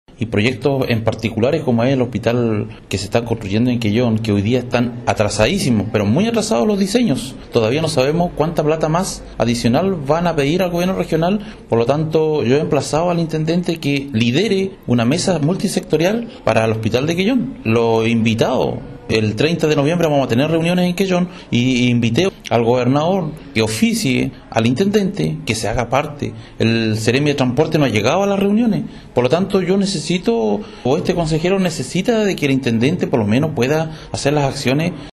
Cristian Miranda, Consejero Regional por Chiloé